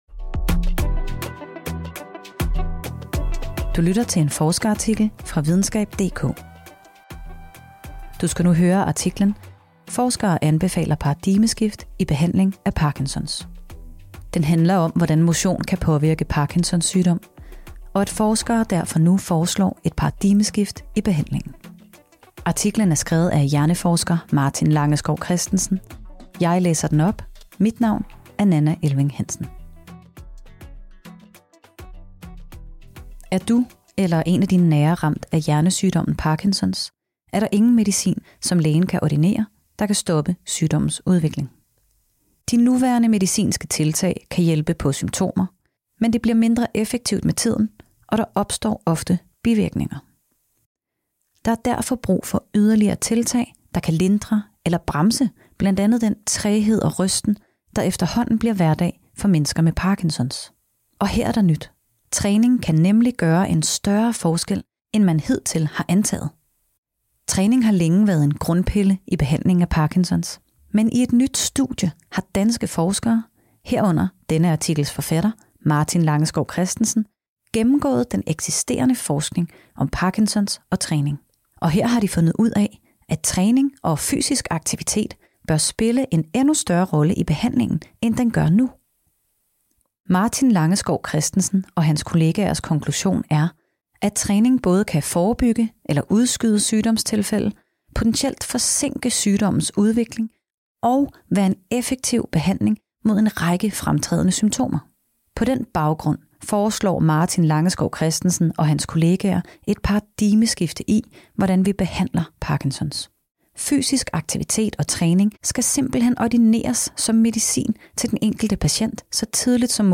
Den oplæste artikel handler om, hvordan motion kan påvirke Parkinsons sygdom - og at forskere derfor nu foreslår et paradigmeskifte i behandlingen.